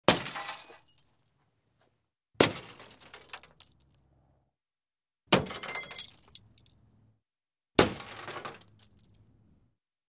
Звуки разбивания машины
На этой странице собрана коллекция реалистичных звуков разбивания автомобиля. Вы можете слушать и скачивать эффекты битья стекол, ударов по металлу кузова и пластику фар.